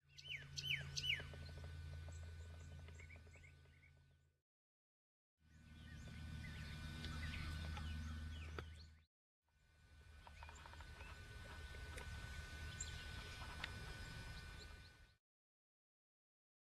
Greater Roadrunner  MOV  MP4  M4ViPOD  WMV